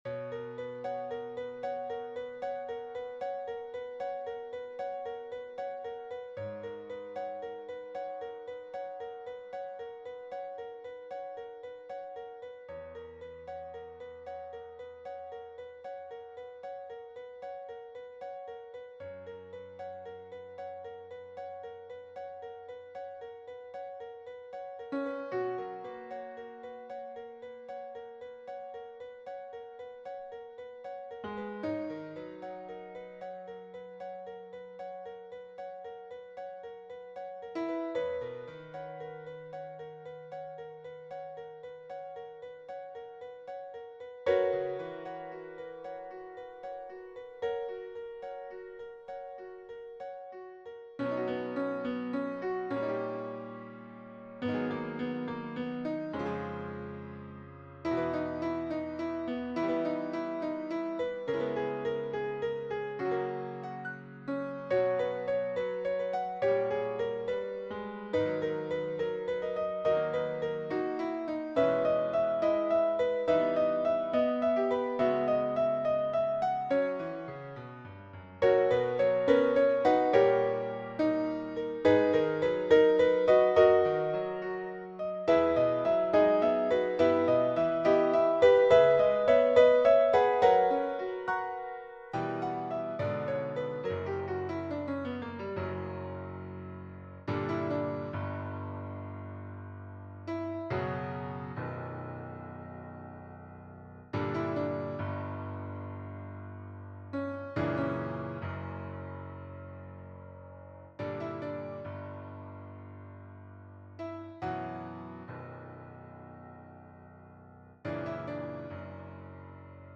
3-part female choir, SSA and piano
世俗音樂
音樂以平穩的三連音開啟序幕，聲部逐一進入，彷彿每一個獨立的靈魂在低聲傾訴。
音樂最終轉向大調，帶來希望的光芒。
樂曲尾聲由高至低的聲部依序傳遞旋律，音響逐漸收斂，最終歸於平靜。